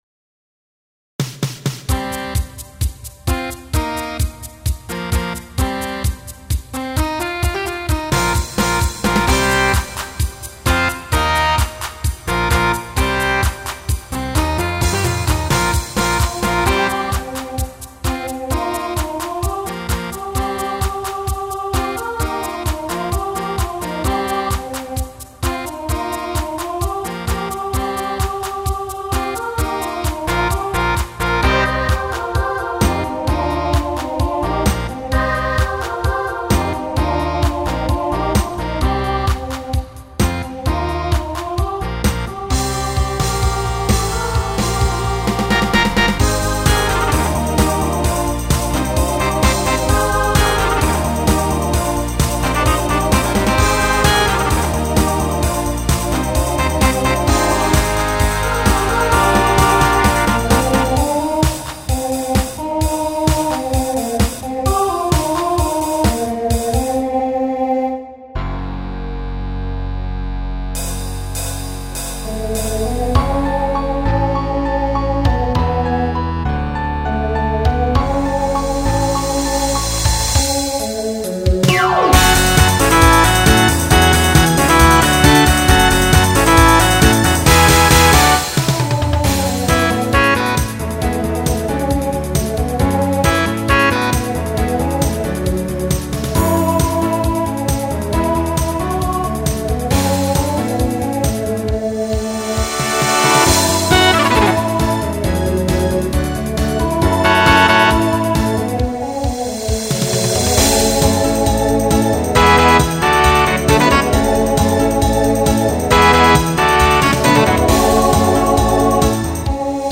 Genre Country , Pop/Dance , Rock
Voicing Mixed